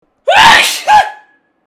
Funny Sneezing Sound Sound Button: Unblocked Meme Soundboard